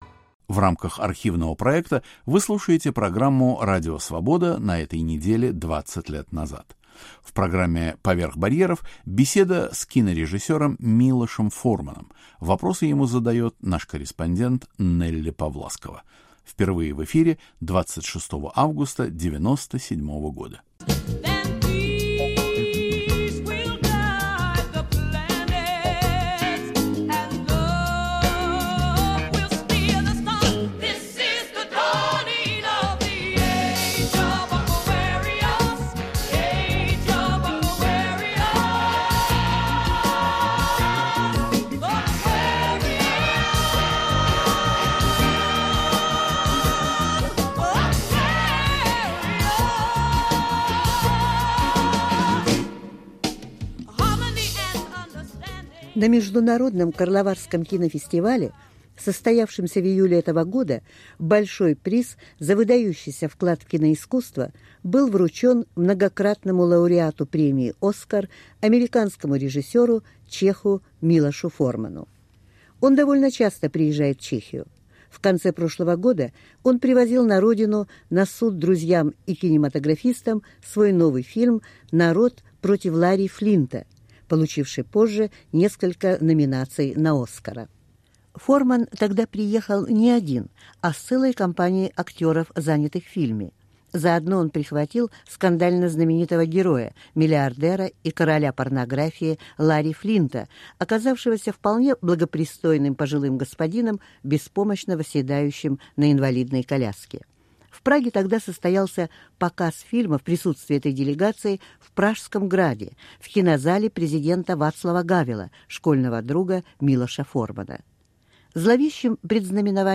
Беседа с Милошем Форманом (1997)